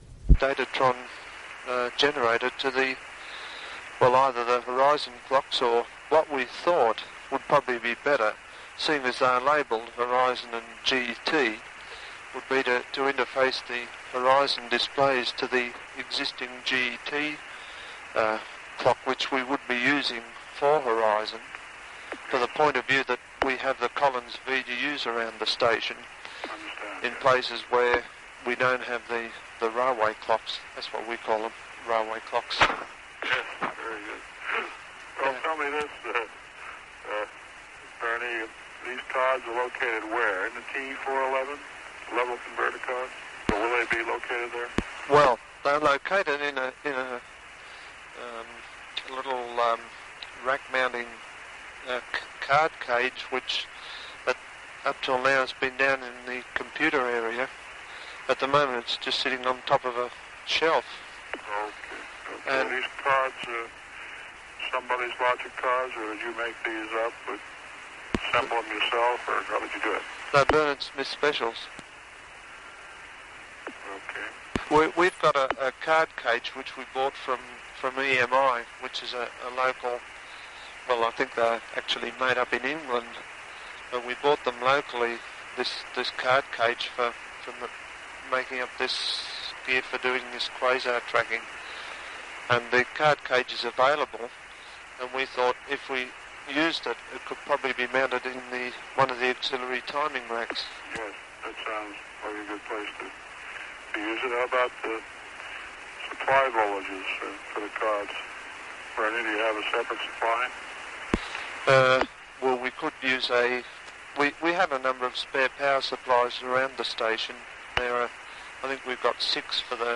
It would have been recorded around 1972 or 73.
the 9 minute conversation (a 3.5MB mp3 file).